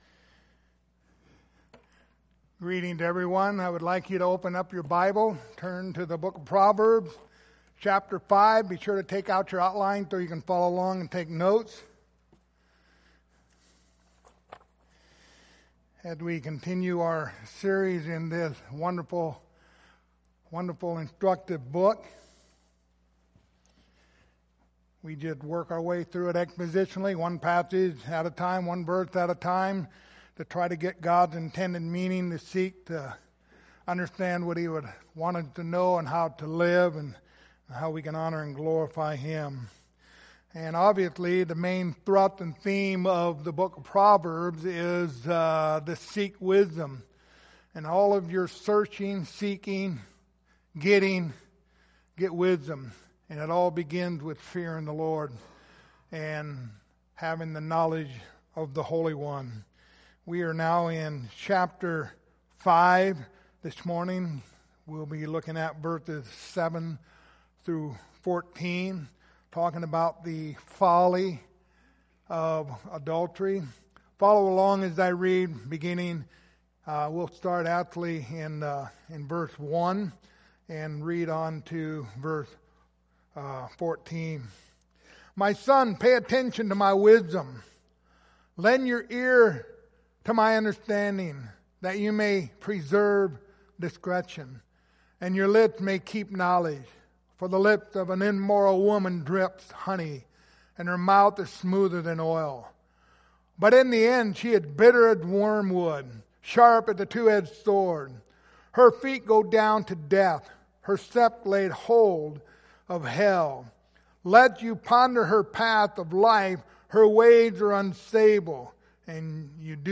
The Book of Proverbs Passage: Proverbs 5:7-14 Service Type: Sunday Morning Topics